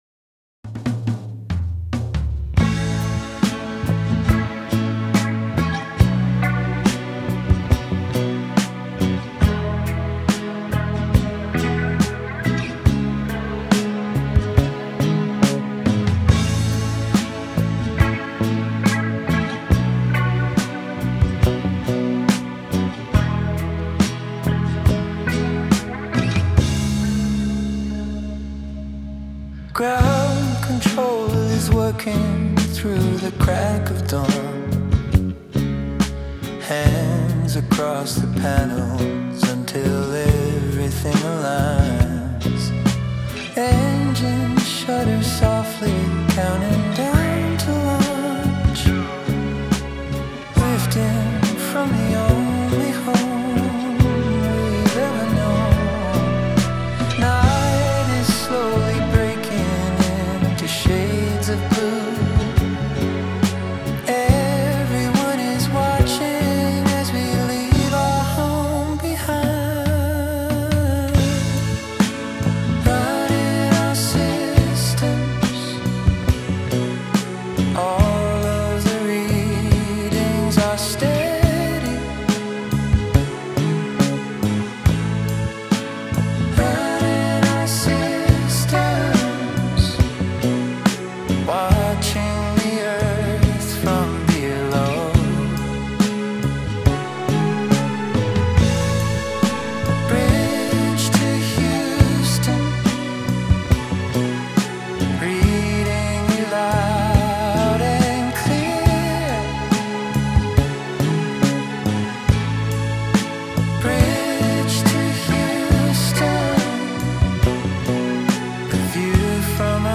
Bridge To Houston - 70s sounding mix
I've tried some stuff like high-pass the bass at 150Hz and make ot sound more "boxy", make the drums sound very tight and dry and make the strings feel less high-quality.
NOTE: The vocals are currently an AI blueprint.
I've added some soundeffects and small stuff here and there to make stuff happen from time to time.